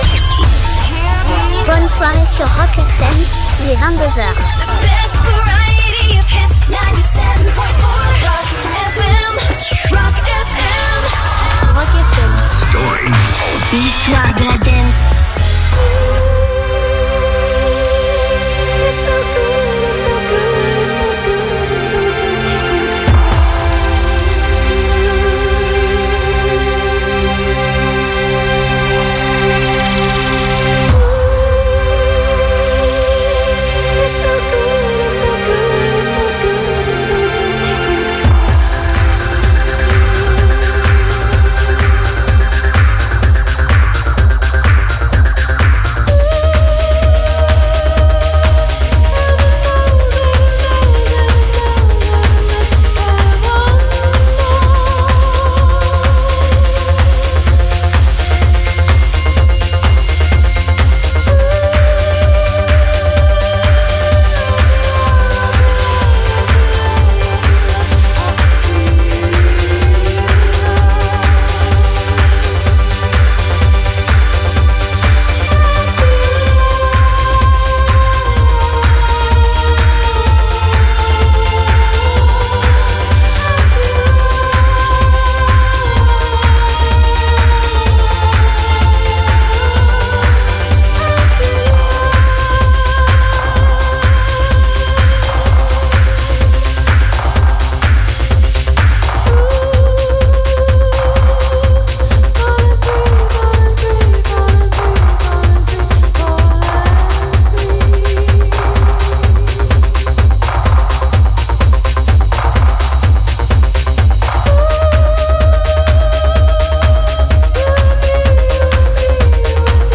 De 22h à 23h, une heure de pure son dance des années 90.2000